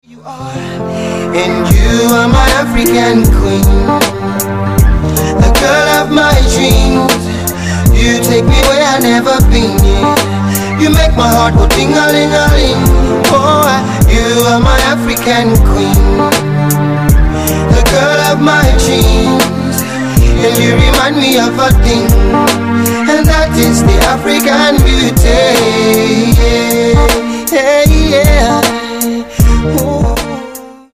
(it’s a guy)